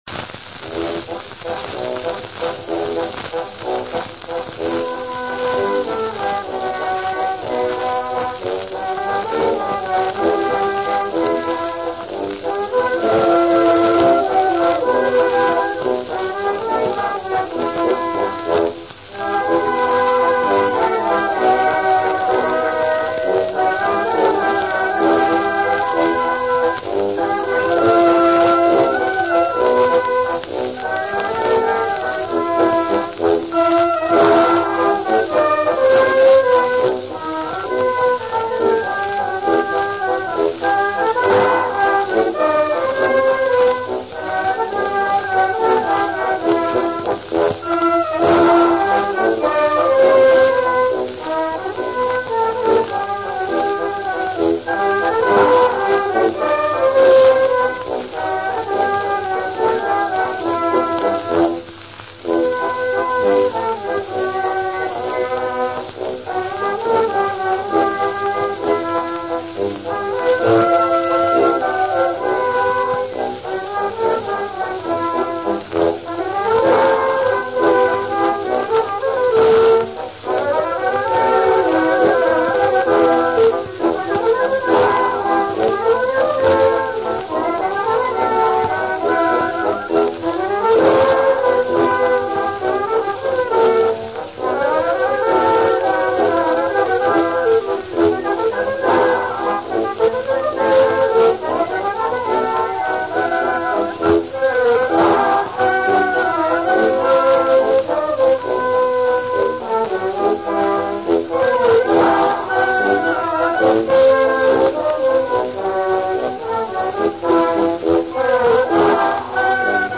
Вальс Ильи Шатрова «Дачные грёзы». Исполняет оркестр общества «Экстрафон»
Чуть-чуть повеселее, чем совсем уж «похоронный» настрой вальса «На сопках Маньчжурии», но в целом… не слишком ведь весело, правда?..